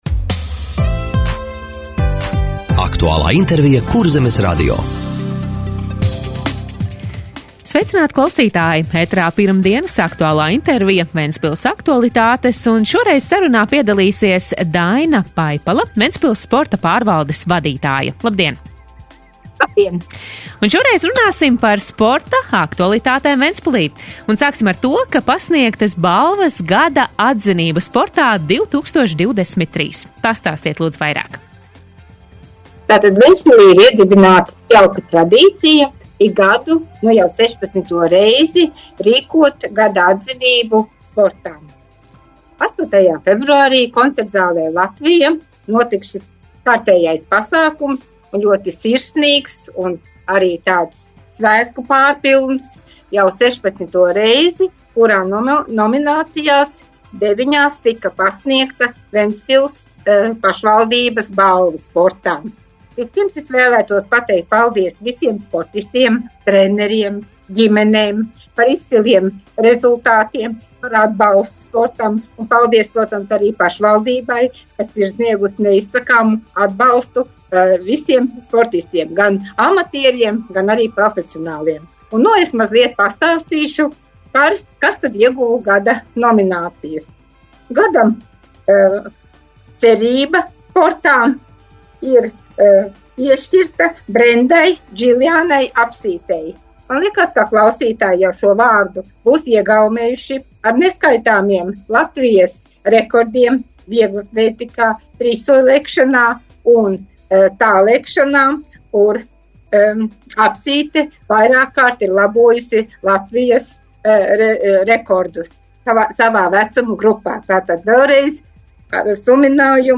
Radio saruna – Pasniegtas balvas “Gada atzinība sportā 2023”